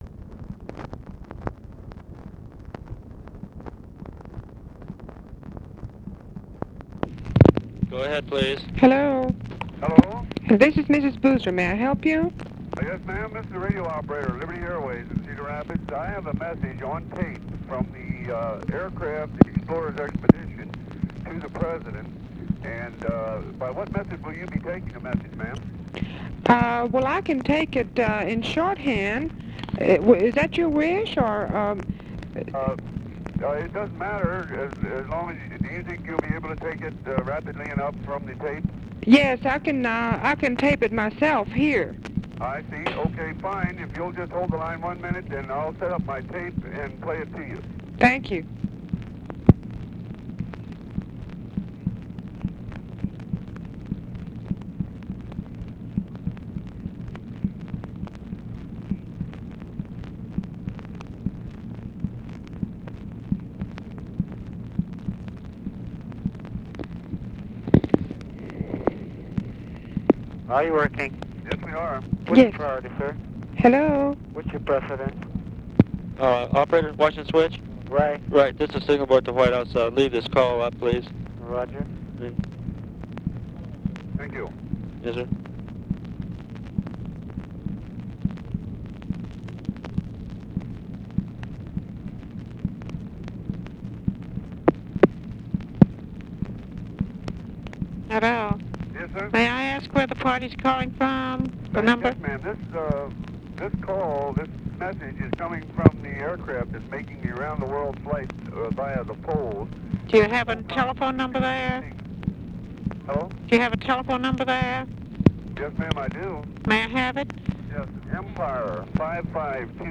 OFFICE CONVERSATION